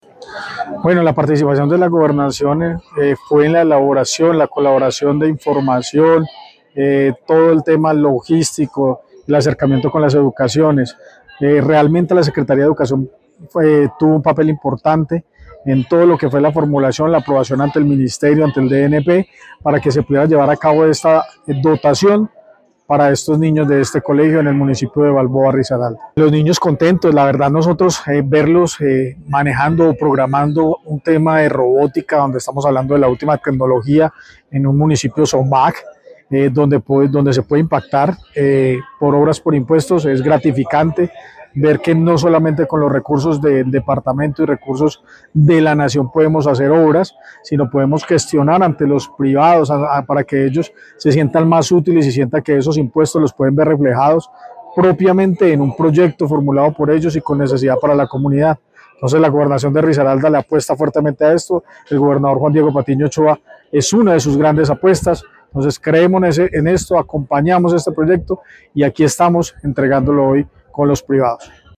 ESCUCHAR AUDIO ÓSCAR ALEXIS SANABRIA CHICA SECRETARIO DE PLANEACIÓN DE RDA